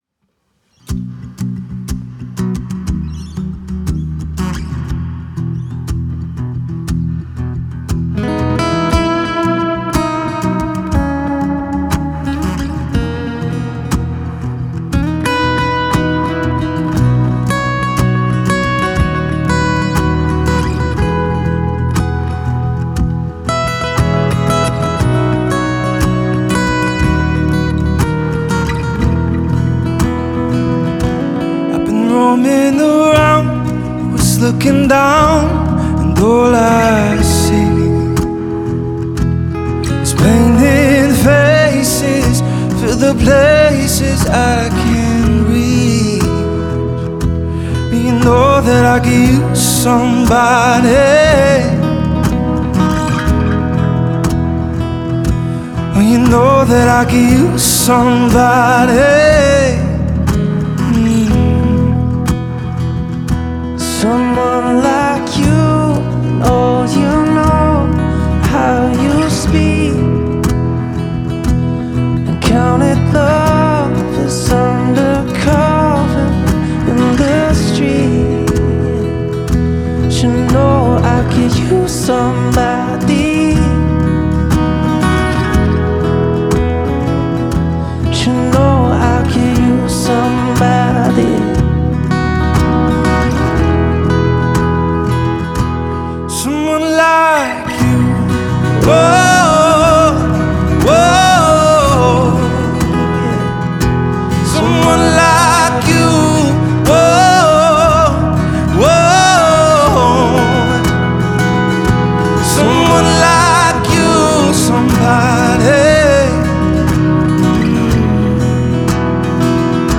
Dual Vocals | Two Guitars | DJ